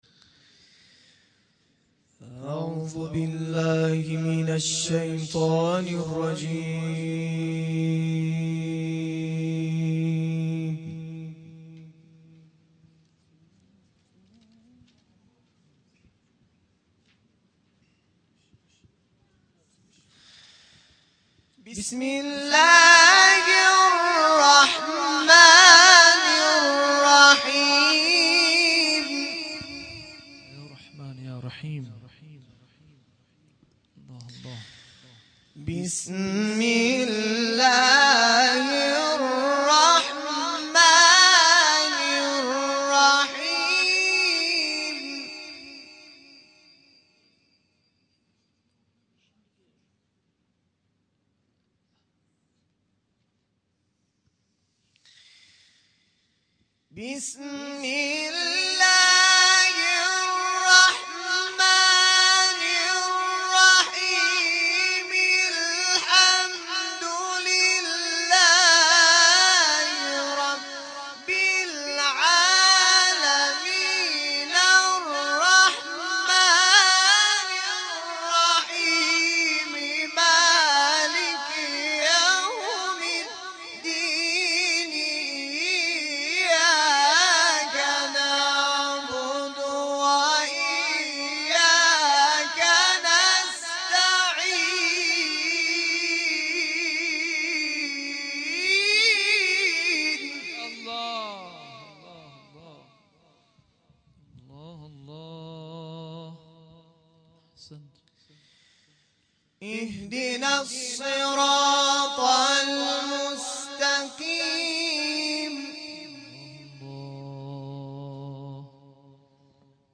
همخوانی دوقلوهای قرآنی از سوره «فاتحه» و«شمس» + صوت